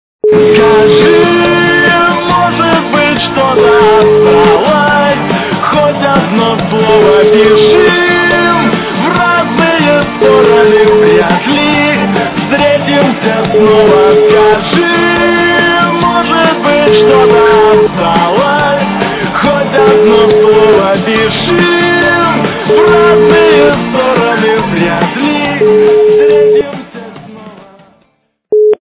русская эстрада
качество понижено и присутствуют гудки.